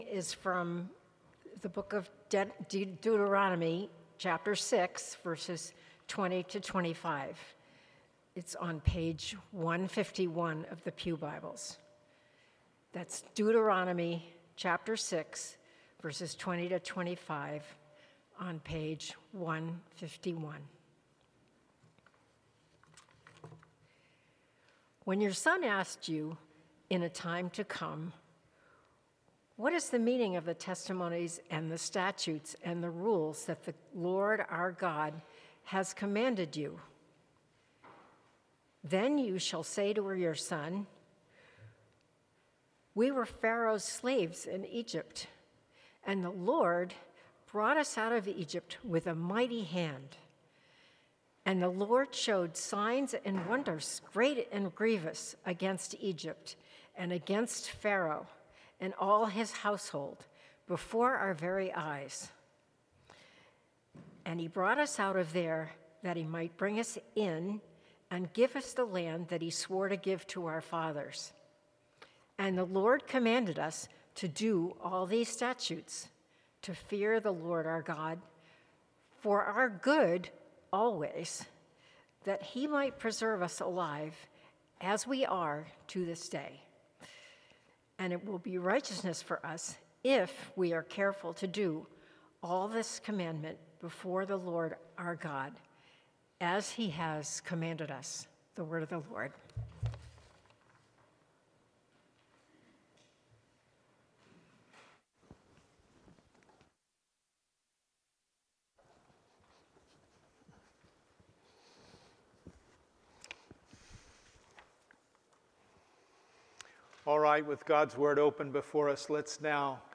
Passage: Deuteronomy 6:24 Sermon